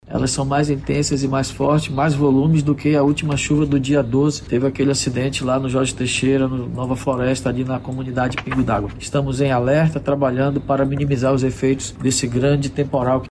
O prefeito da Manaus, Davi Almeida, destacou que chuva deste sábado foi mais intensa do que a registrada no último dia 12, quando oito pessoas morreram soterradas na zona Leste.